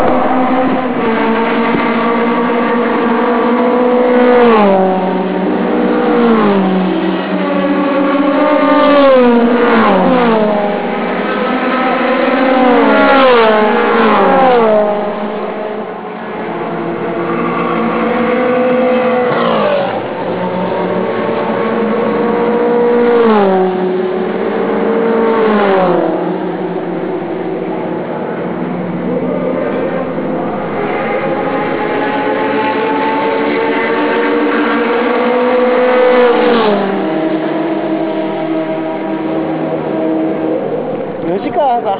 こちら(25秒202KB)オープニングラップの音は
ちなみに先頭はJPE、２台目はVX-R、３台目はR500です